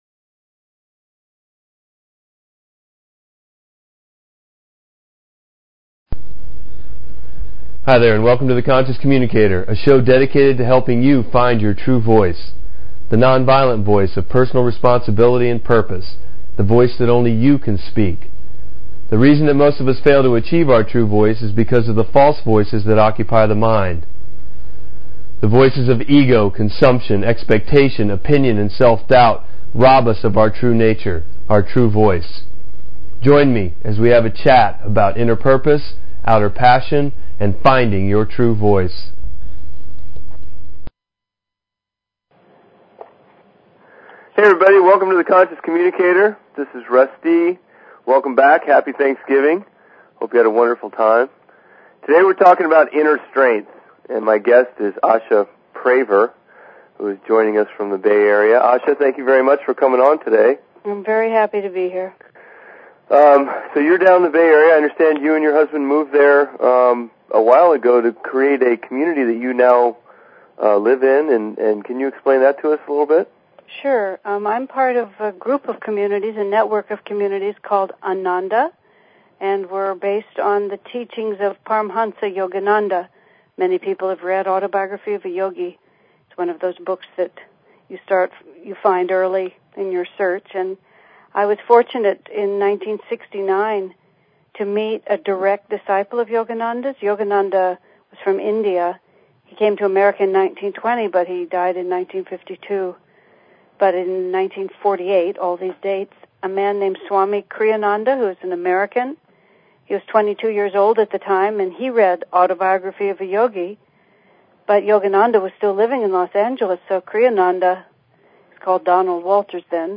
Talk Show Episode, Audio Podcast, The_Conscious_Communicator and Courtesy of BBS Radio on , show guests , about , categorized as